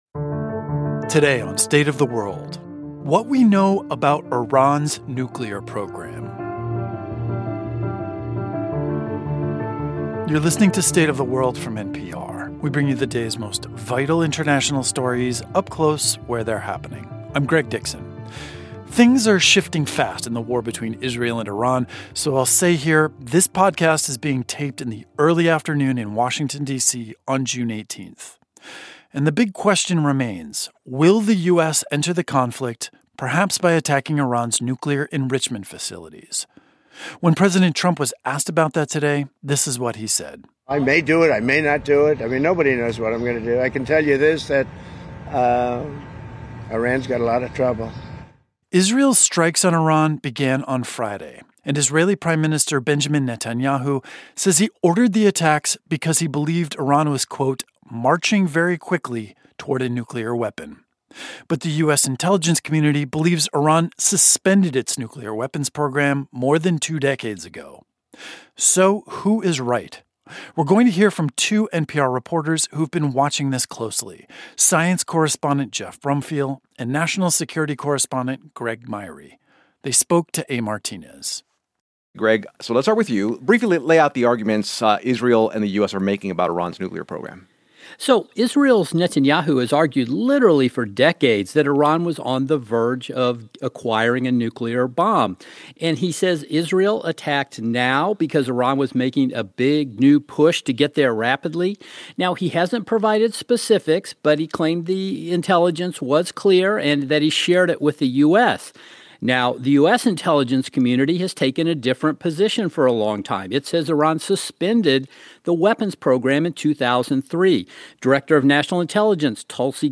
The U.S. intelligence community says Iran suspended its nuclear weapons program decades ago. We hear from two NPR correspondents who are watching this very closely to find out who is right.